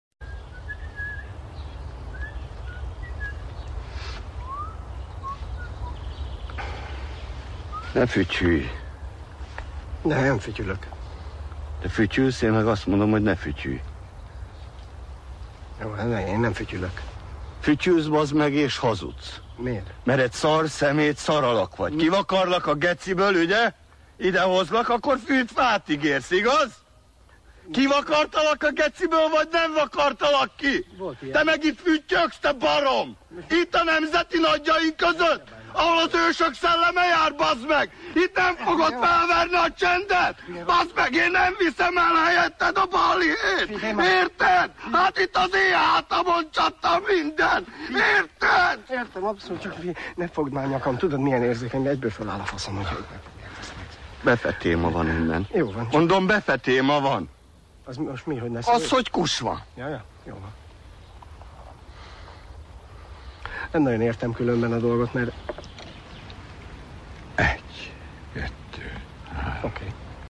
temetõi hangrészlet a "Nekem lámpást adott az úr..." c. filmbõl,